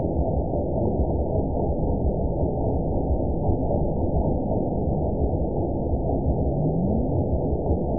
event 917319 date 03/27/23 time 19:30:39 GMT (2 years, 7 months ago) score 8.94 location TSS-AB05 detected by nrw target species NRW annotations +NRW Spectrogram: Frequency (kHz) vs. Time (s) audio not available .wav